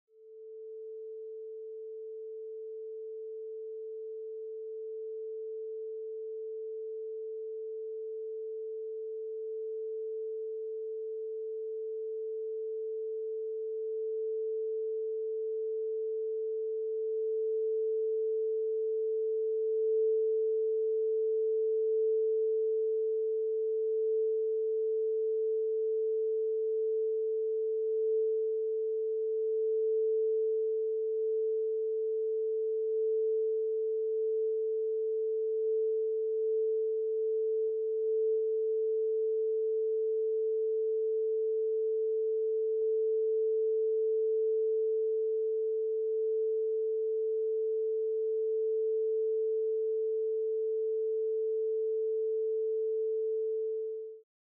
440HZ Binaural sound Stimulating sound effects free download